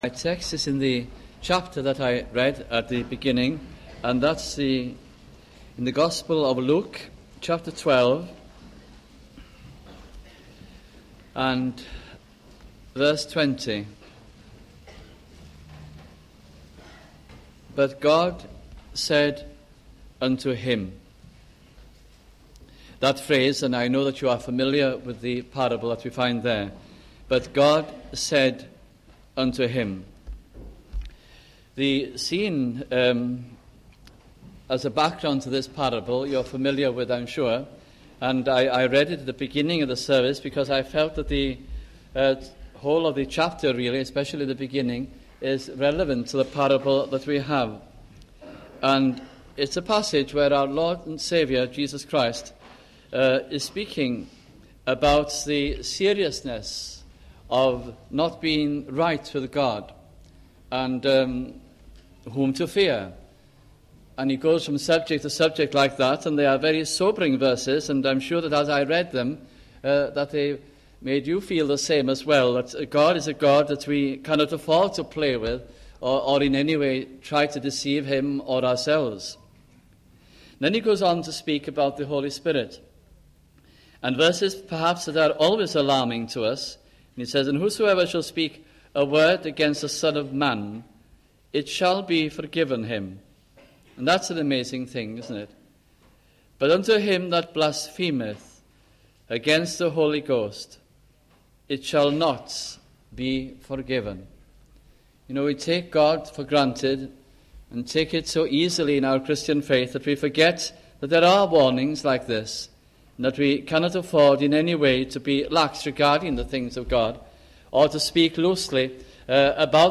» Luke Gospel Sermons